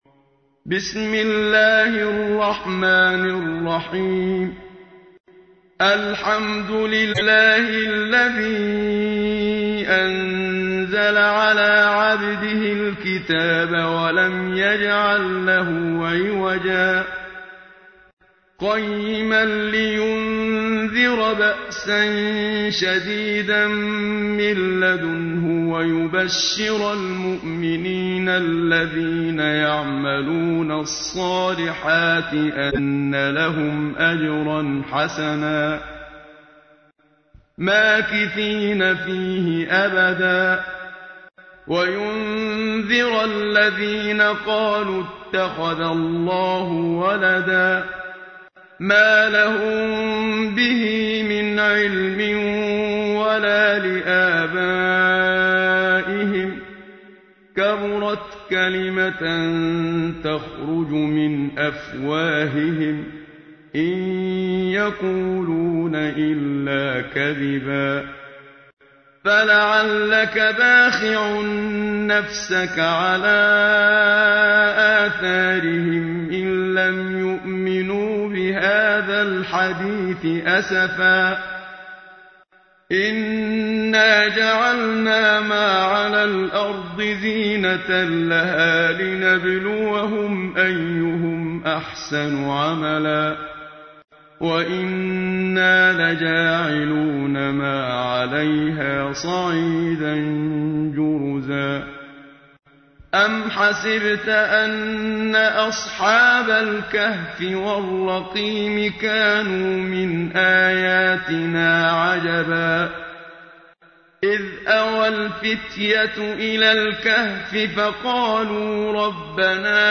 تحميل : 18. سورة الكهف / القارئ محمد صديق المنشاوي / القرآن الكريم / موقع يا حسين